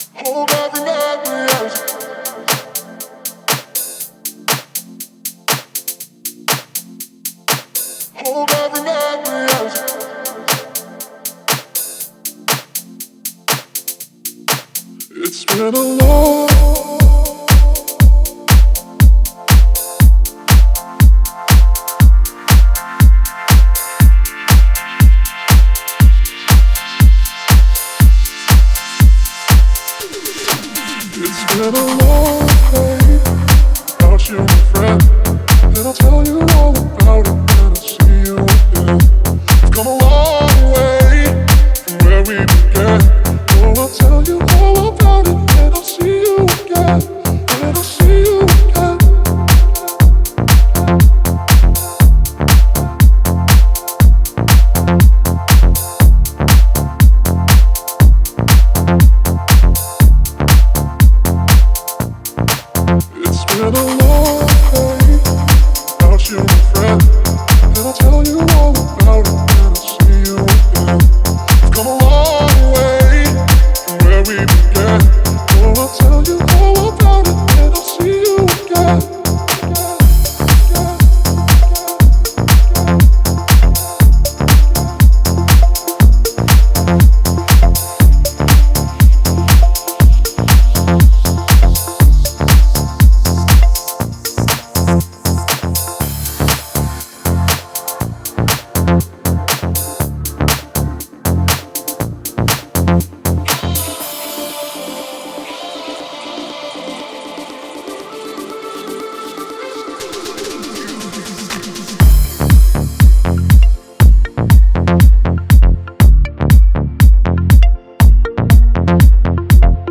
ژانر: بی کلام
🎵 موزیک بی کلام🎶🤩